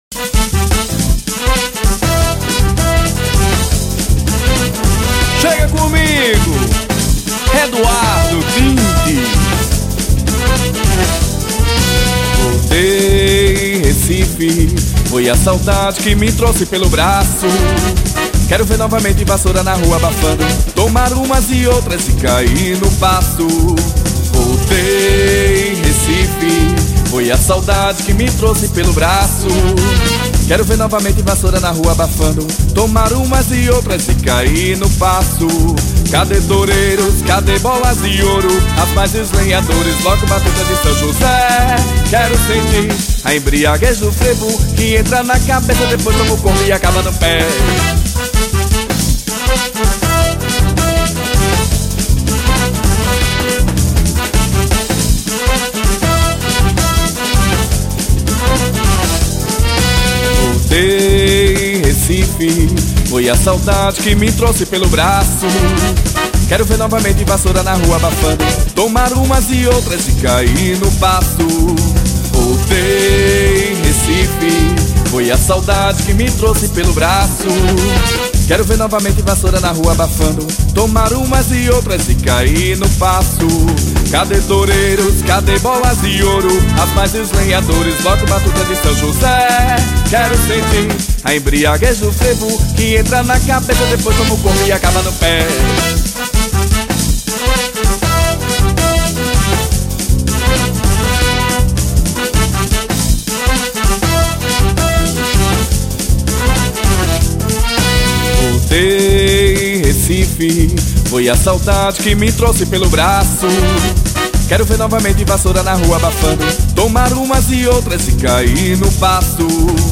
Frevo.